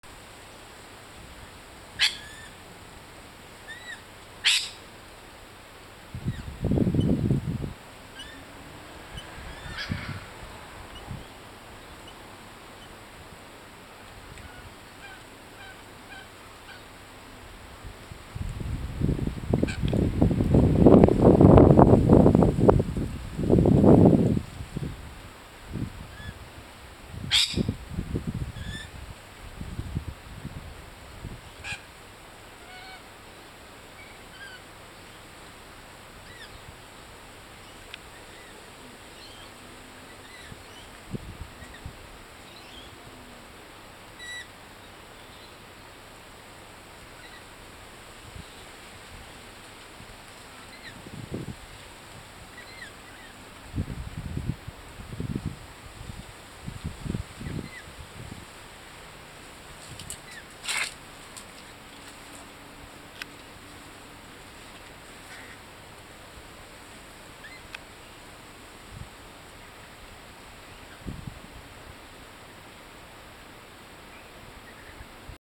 Lorikeets And A Sleeping Flying Fox In Our Garden